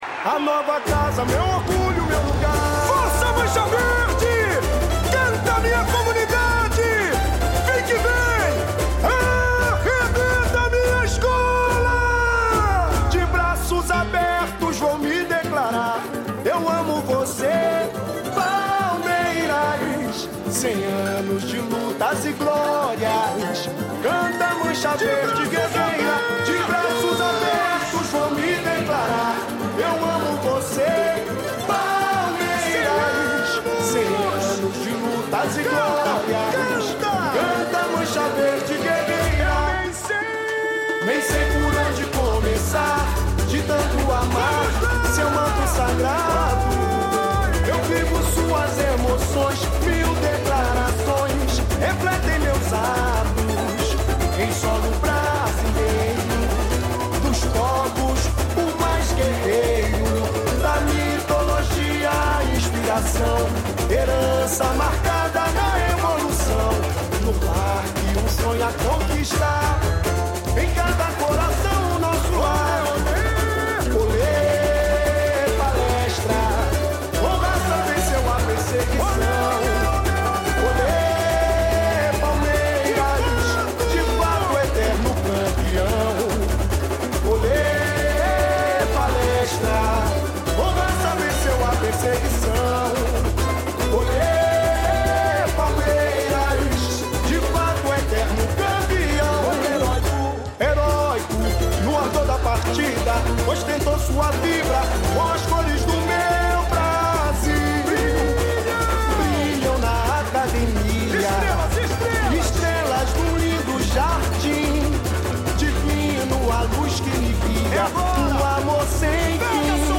Intérprete: